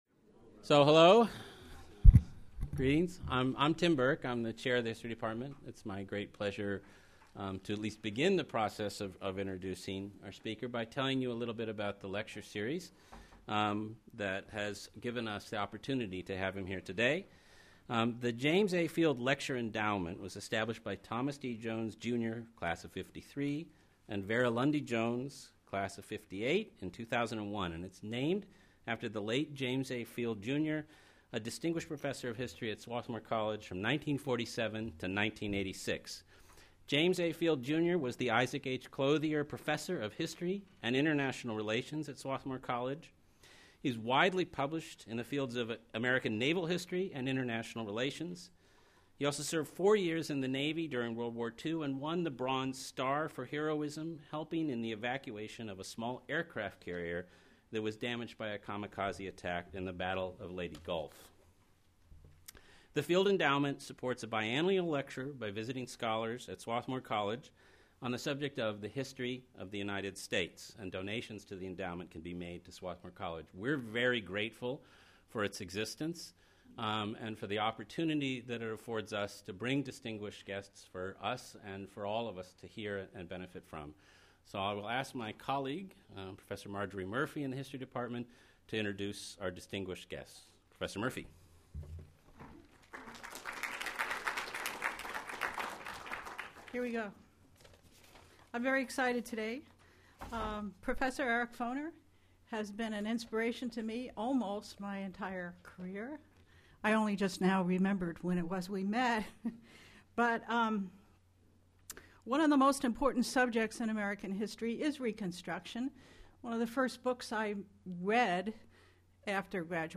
Eric Foner, DeWitt Clinton Professor of History at Columbia University, gives the 2013 James A. Field lecture.